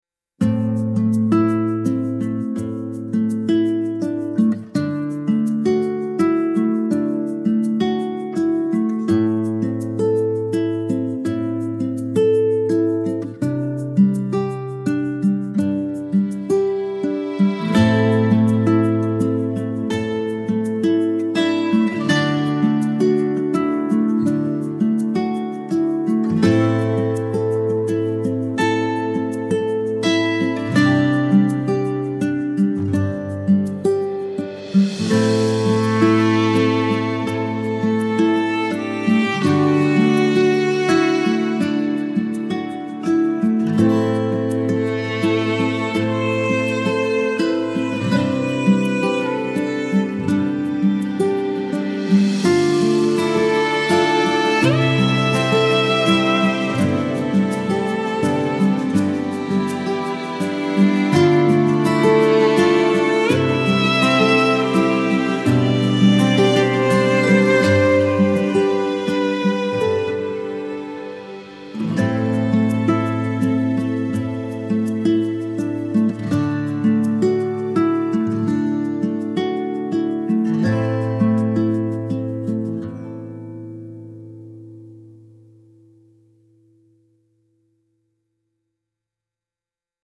organic folk-inspired instrumental with fingerpicked guitar and light strings